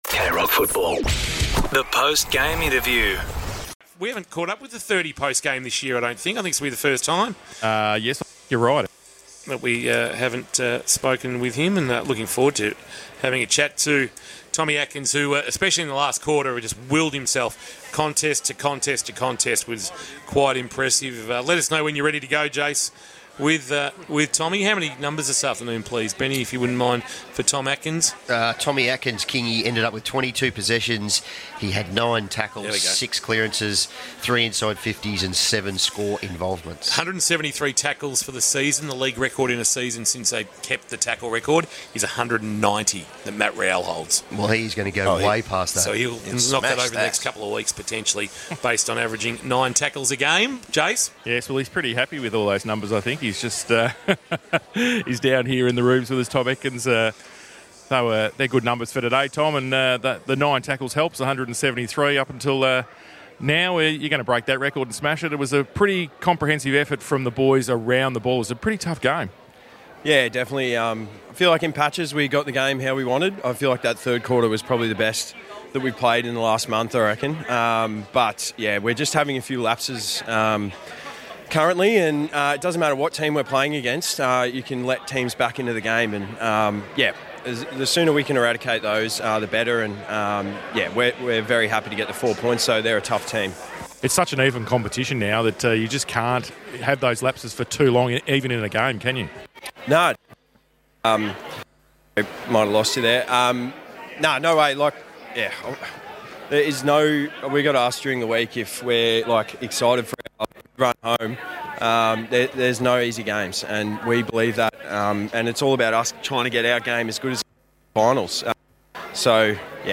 2025 - AFL - Round 19 - Geelong vs. St Kilda: Post-match interview